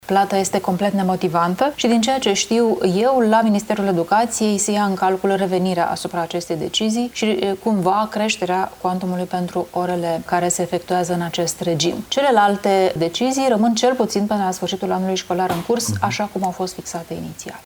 Luciana Antoci, consilier de stat la Cancelaria premierului Bolojan,  a declarat într-un interviu la TVR Iași că „Ministerul Educației ia în calcul revenirea asupra acestei decizii”.